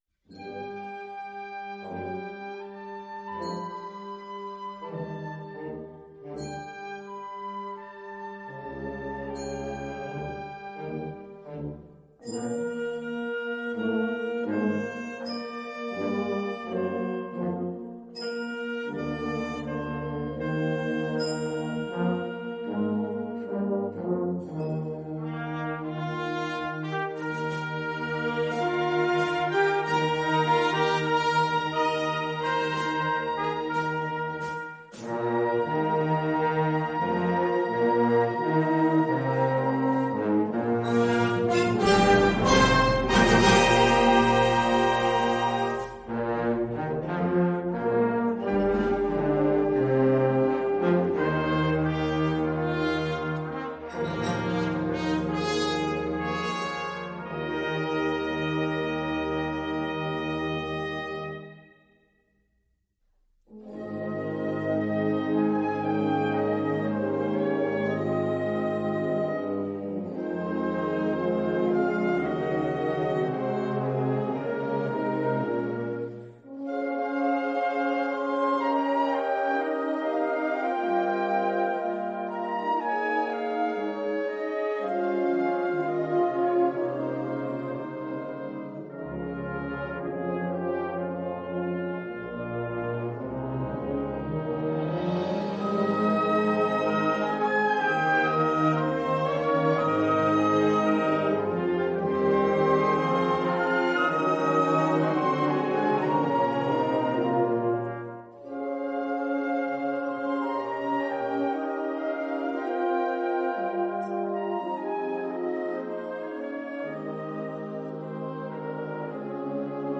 Genre musical : Classique
Collection : Harmonie (Orchestre d'harmonie)
Oeuvre pour orchestre d’harmonie.